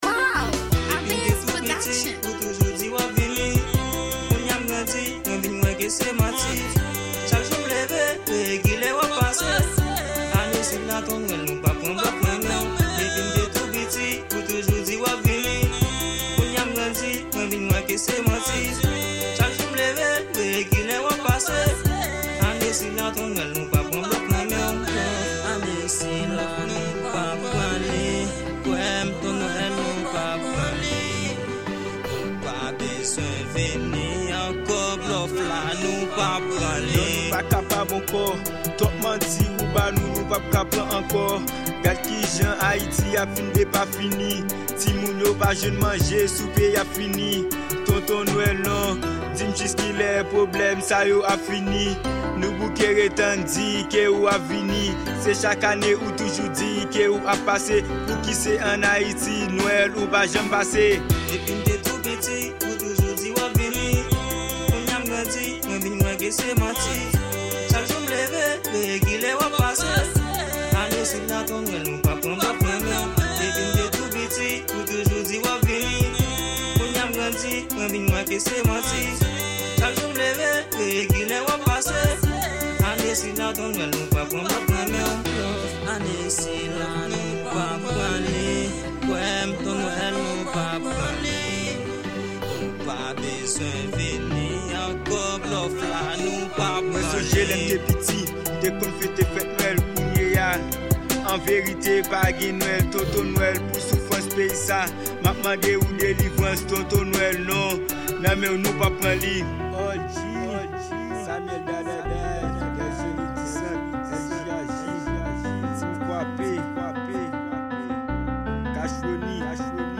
Genre: Rnb , Rap.